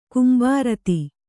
♪ kumbārati